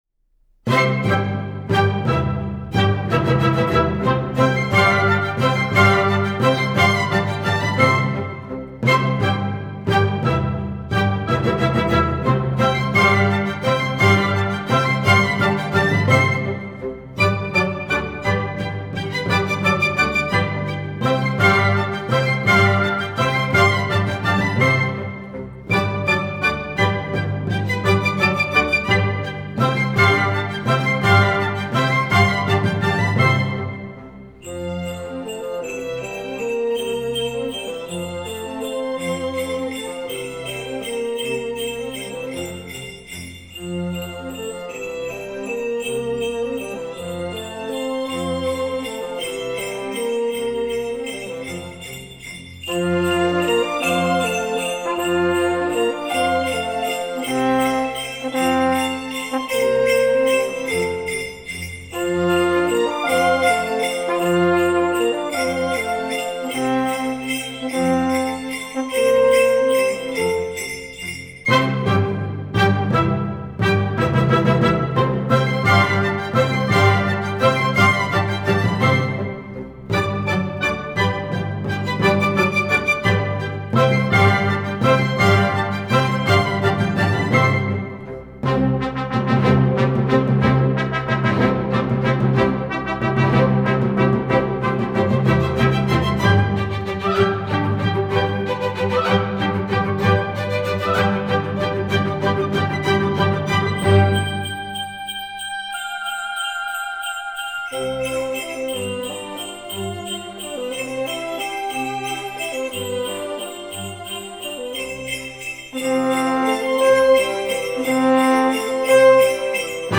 Orquesta
Música clásica